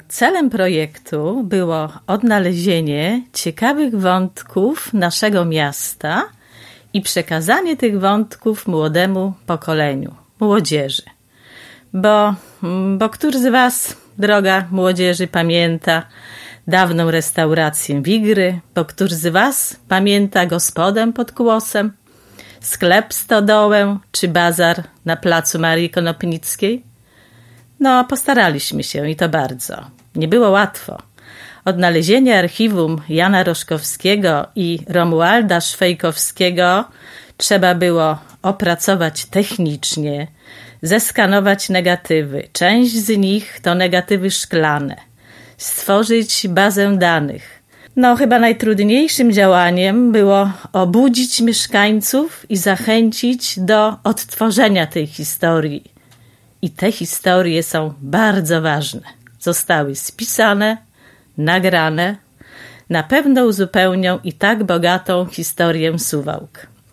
Portret suwalczan po pół wieku – Radio 5